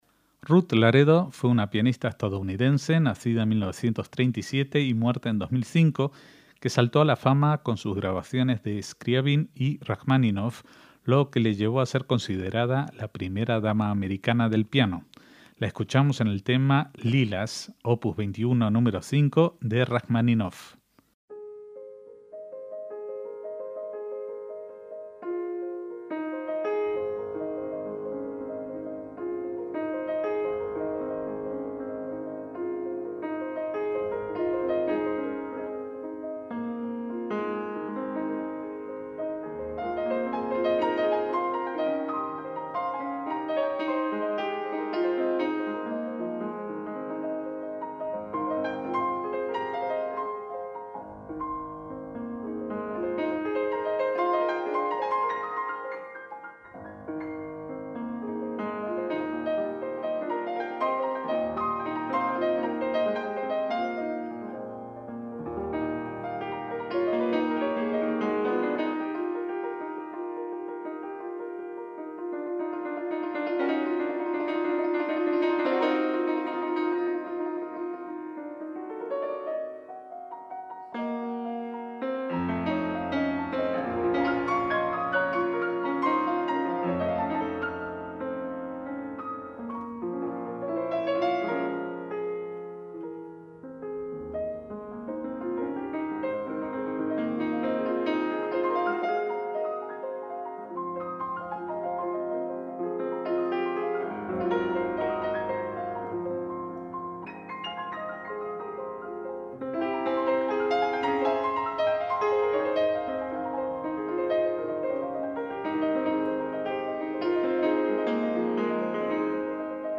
MÚSICA CLÁSICA
fue una pianista estadounidense